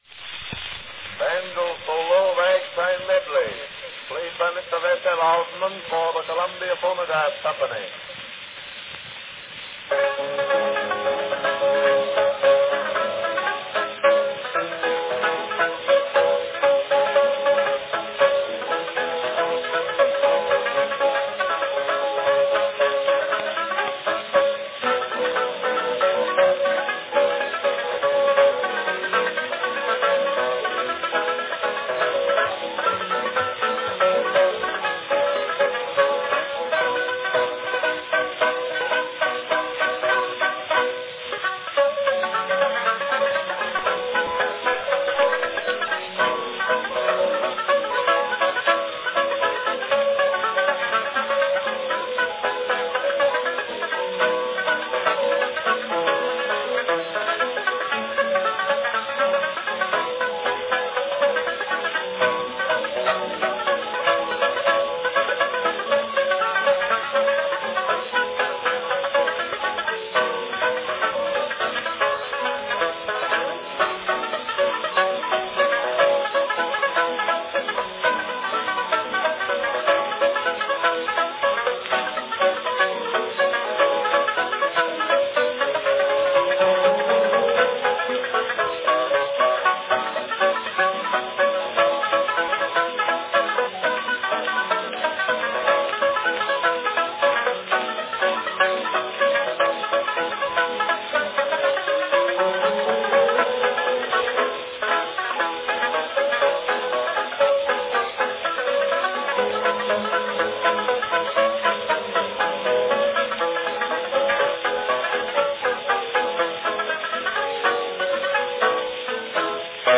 A popular banjo selection played by the great Vess L. Ossman – Ragtime Medley, from 1900.
Cylinder # 3830 G (5-inch "grand" cylinder)
Category Banjo solo
Performed by Vess L. Ossman
Announcement "Banjo solo, Ragtime Medley, played by Mr. Vess L. Ossman for the Columbia Phonograph Company."